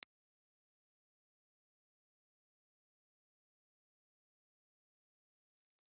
IR file of a Grampian DP1 dynamic microphone.
This, combined with the special acoustic chambers to eliminate resonances, produces a very level response curve.